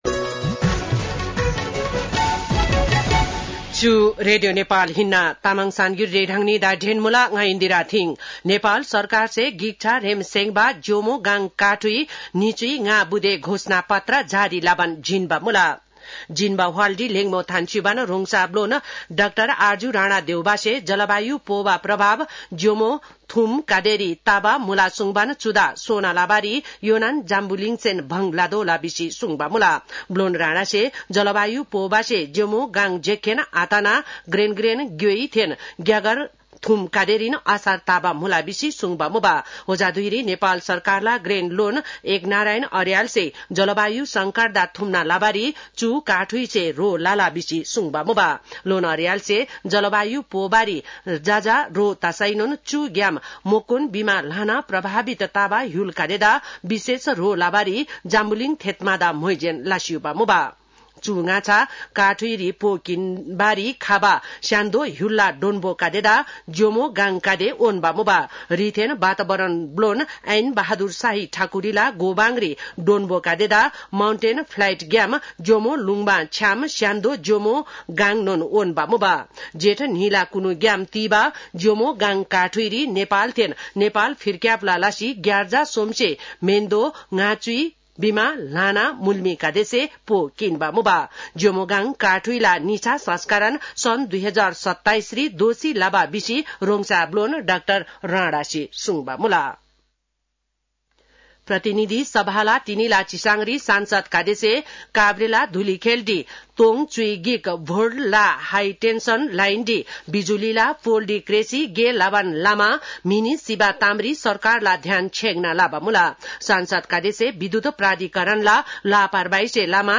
तामाङ भाषाको समाचार : ४ जेठ , २०८२